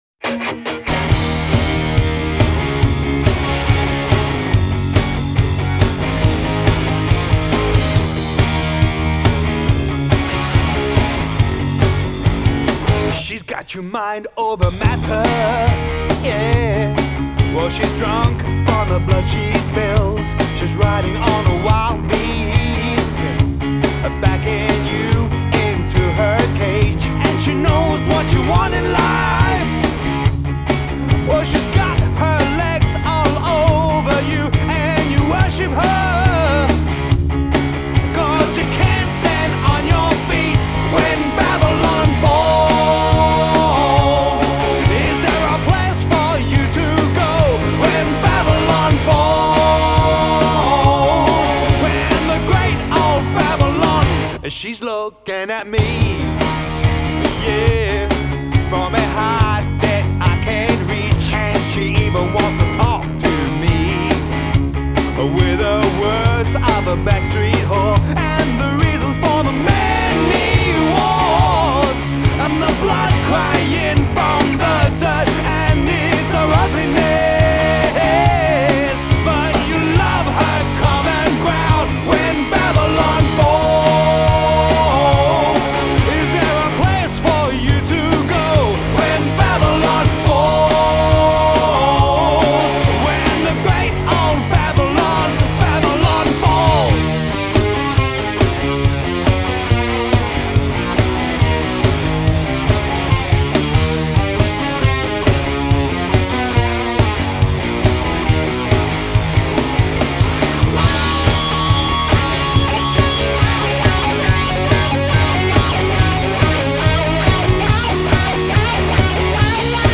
singer and guitarist
Drums
Bass
Keyboards